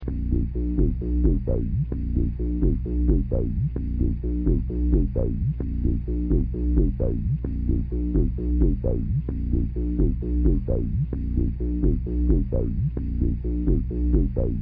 Et voici quelques pièces pour TB-303...